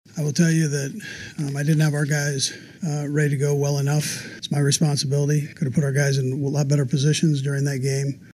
Chiefs head coach Andy Reid says he didn’t do his part in making sure his team was ready.